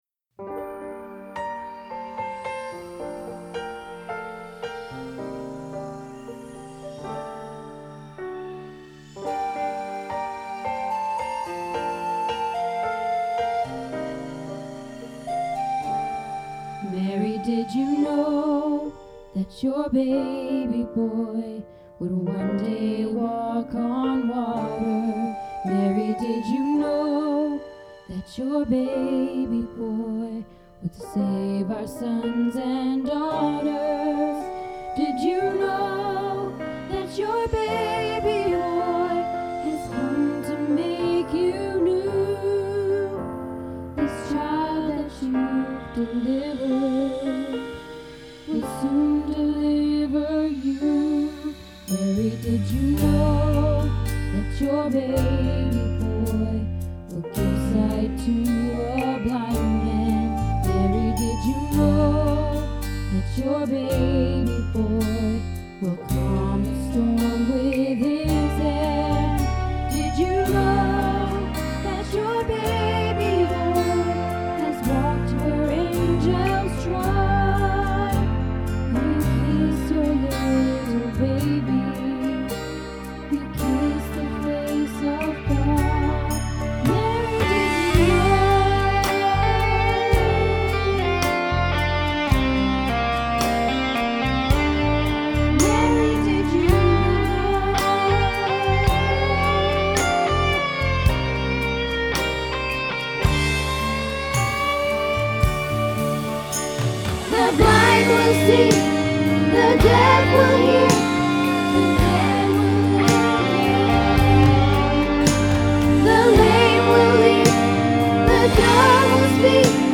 Mary Did You Know - Soprano